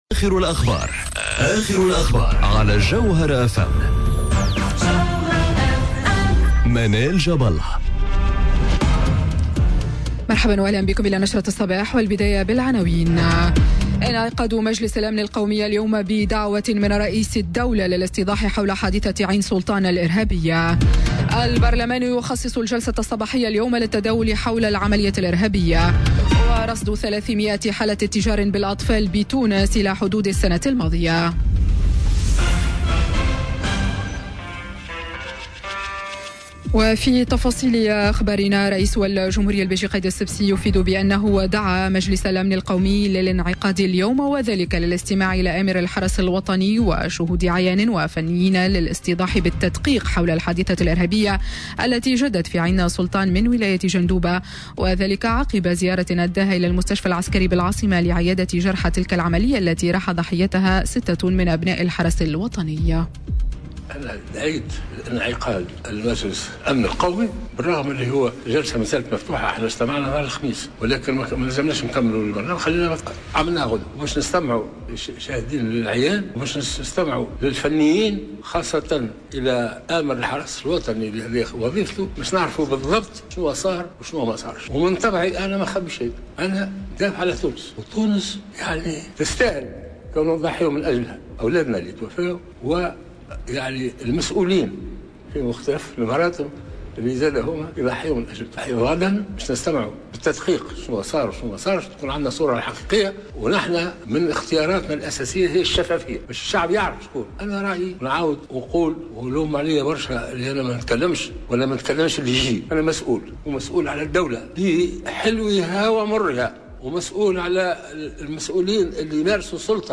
نشرة أخبار السابعة صباحا ليوم الثلاثاء 10 جويلية 2018